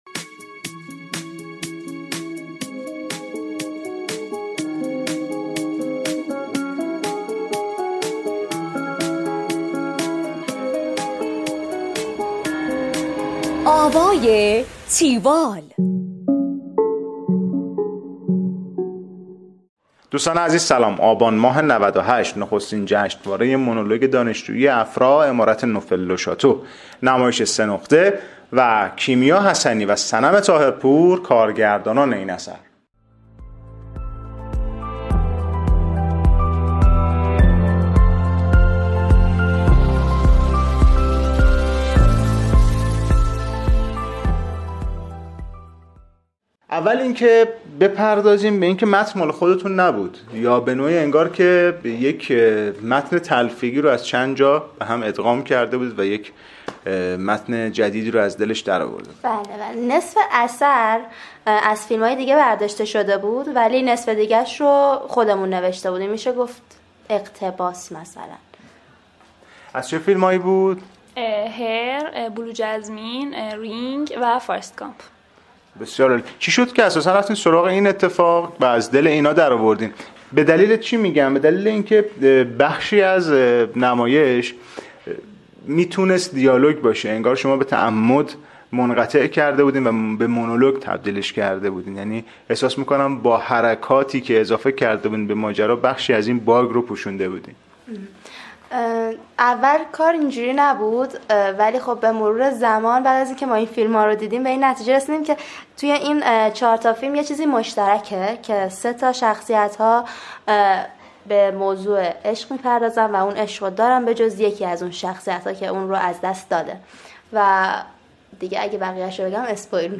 tiwall-interview-senoghteh.mp3